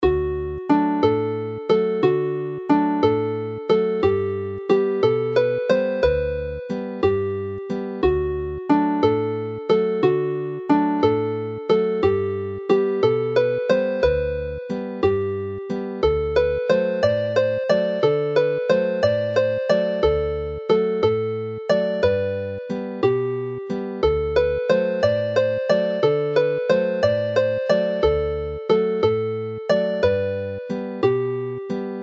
The song Y Gelynnen appears earlier in this collection in a different version; this one is a little more relaxed whereas the jaunty Sbonc Bogel (Belly jerk) which finishes the set is a lively jig.
Play the melody slowly